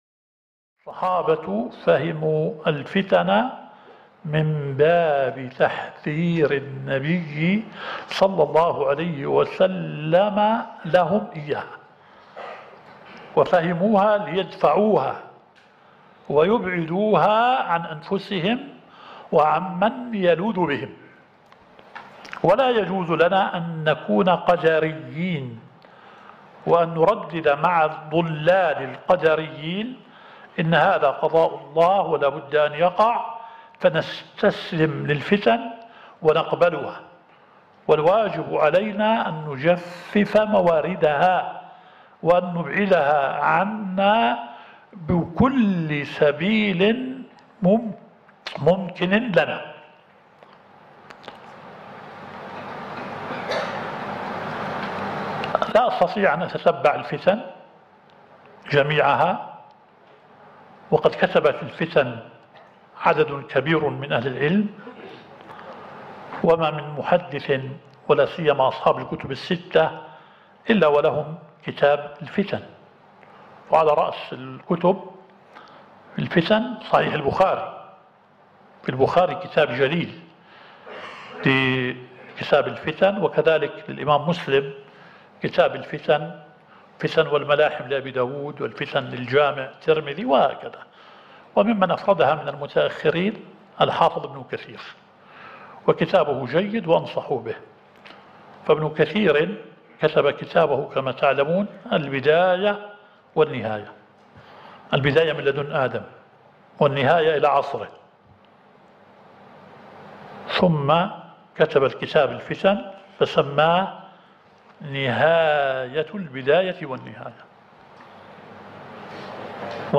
الدورة الشرعية الثالثة للدعاة في اندونيسيا – منهج السلف في التعامل مع الفتن – المحاضرة الأولى.